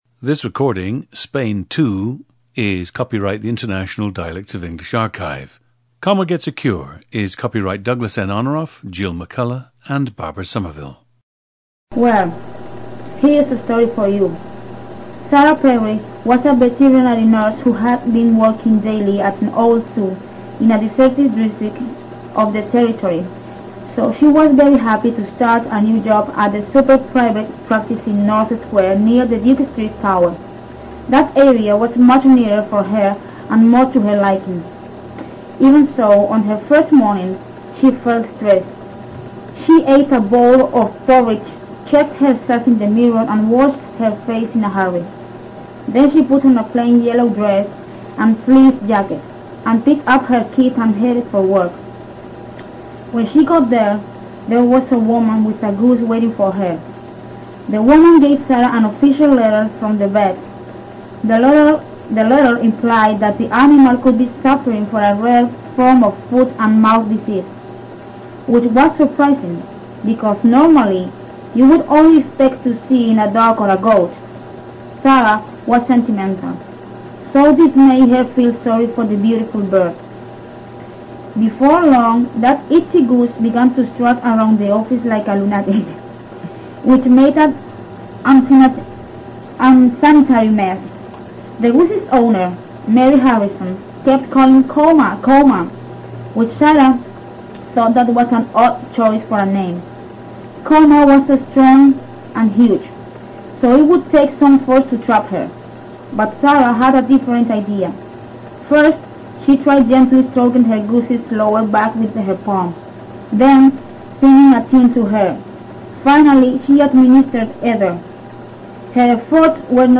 PLACE OF BIRTH: Seville, Spain
GENDER: female
OTHER INFLUENCES ON SPEECH: N/A
Subject has a very strong Southern Spanish (Seville) dialect. There is a very prominent transformation of the “v” sound, becoming “b” in words such as “veterinary” and “very.” The short “i” sound lengthens to “eee” in “it.” She also often adds an intrusive “e” before words beginning with “s,” so “student” becomes “student” and “Spain” becomes “Espain.”
The recordings average four minutes in length and feature both the reading of one of two standard passages, and some unscripted speech.